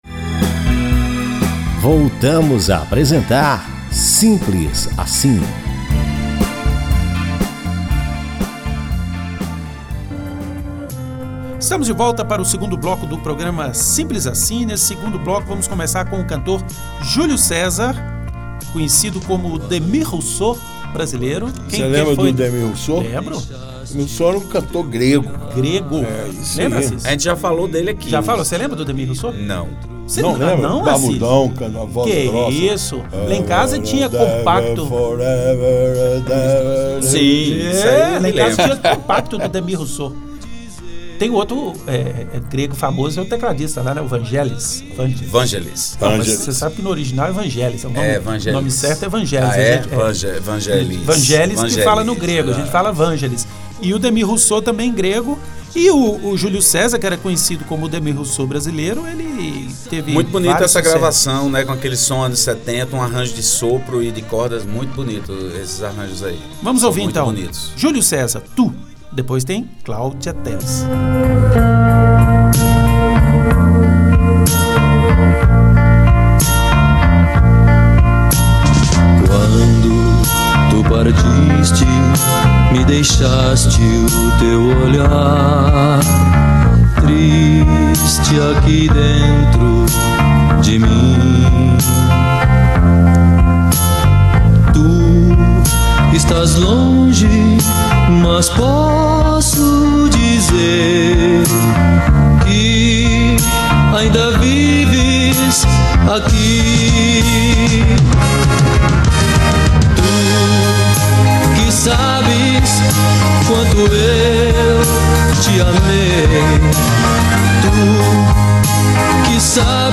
Música Romântica